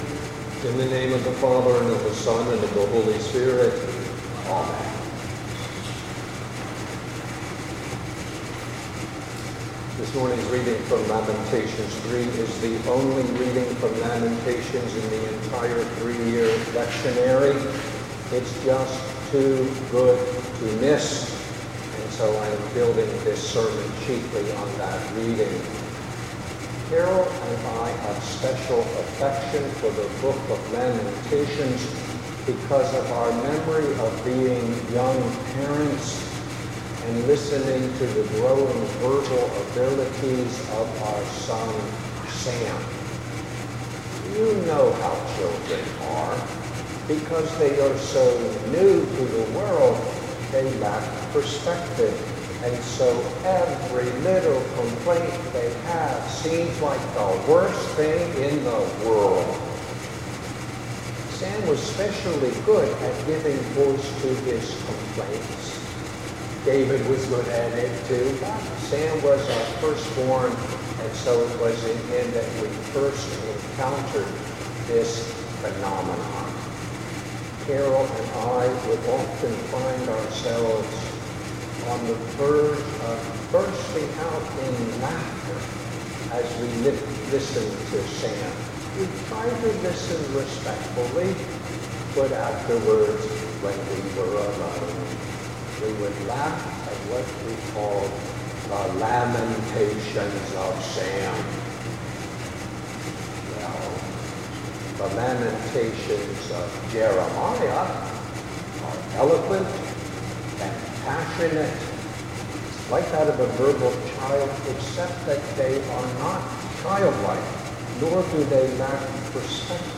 sermon_5_pentecost_2015.mp3